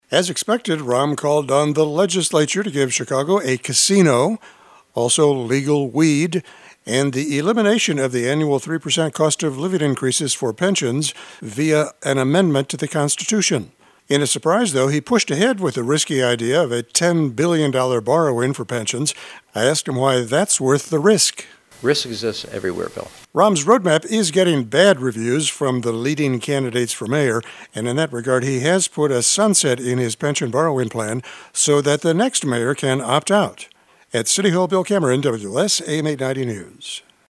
(CHICAGO) At today’s City Council meeting, Mayor rAHM Emanuel officially unveiled his road map to new revenues for the city’s enormous pension liabilities.